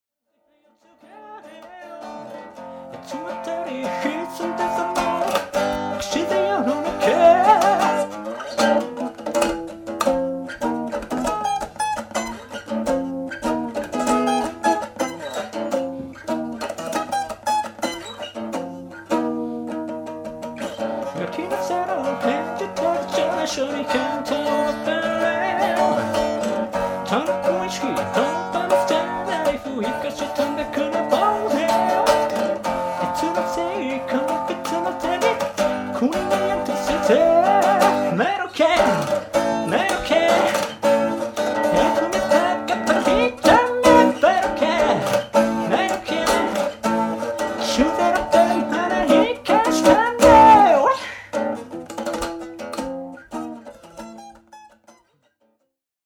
ギター１本とボーカルでわりと複雑な仕掛けをやっています。自分の中では整合性があるのでしょうが、 リズム体のサポートやベースがない状態では聞く側はビートを見失ったり、コードに違和感をおぼえます。
それと発音も歌詞カードを見ないと何を言ってるのかわかりません。
確かに高い声が伸びた時はすばらしい声ですが、音程をはずしてしまっている部分もあるので注意して歌って ください。
またギターのバッキングには和声がプリミティヴなためメロディを支えきれていないところや、リズムが 複雑なあまり弾ききれていない部分が多くあります。